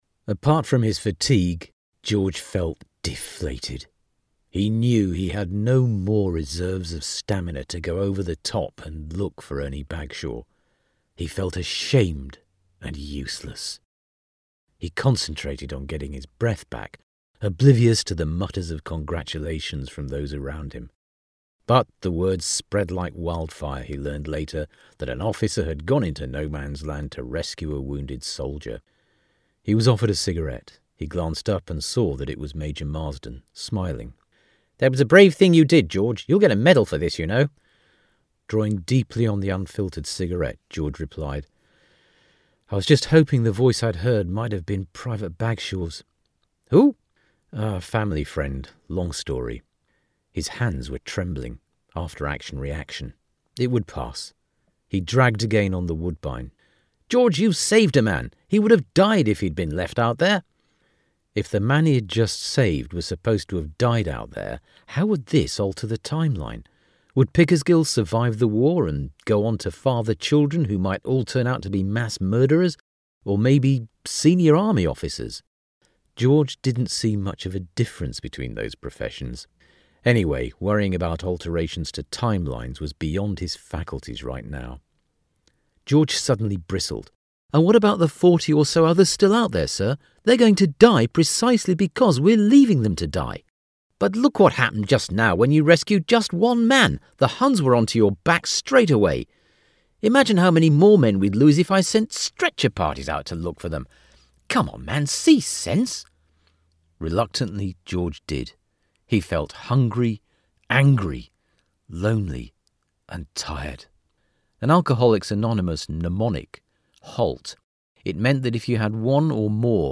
0221Audiobook_sample.mp3